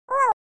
Sound Effects
Wow Cat Sound